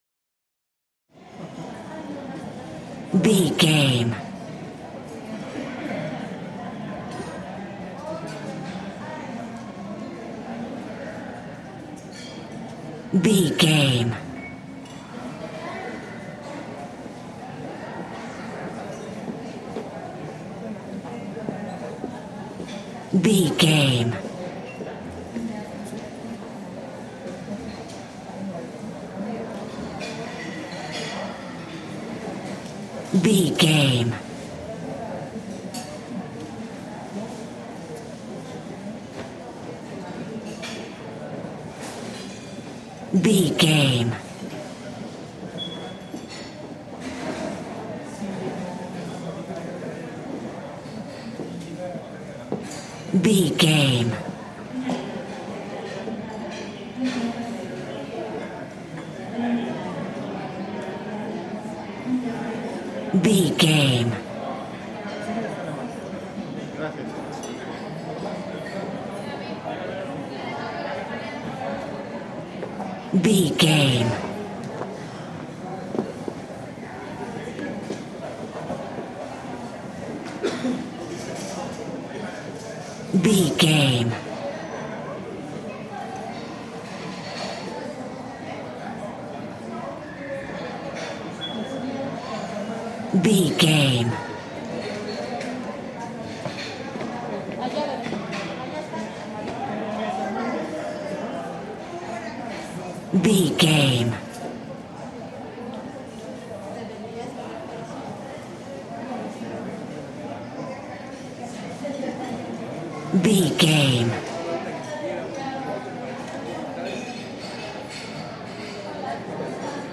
Restaurant crowd medium
Sound Effects
ambience